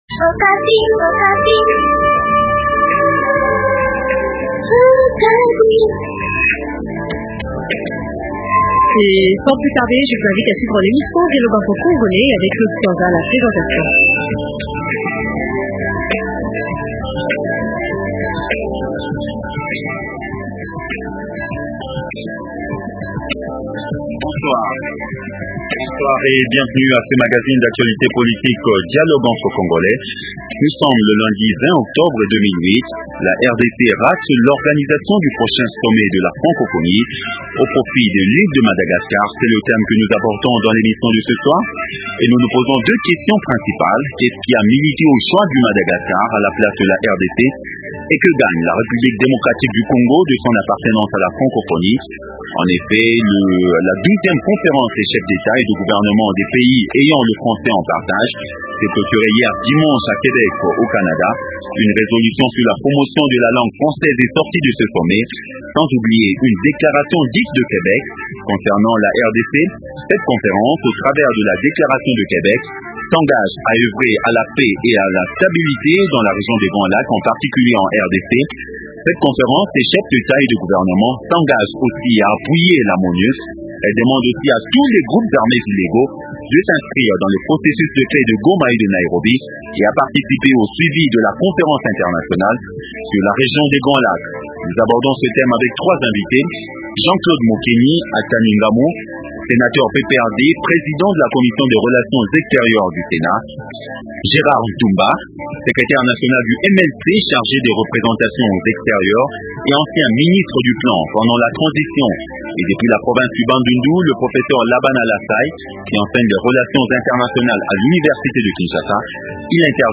- Qu’est-ce qui a milité au choix du Madagascar à la place de la RDC? - Que gagne la République Démocratique du Congo de son appartenance à la Francophonie? Invités : - Jean-Claude Mokeni Ataningamu: Senateur PPRD, président de la commission des relations extérieures du sénat. - Gerard Ntumba : Secrétaire National chargé des représentations extérieures au Mlc et ancien Ministre du plan.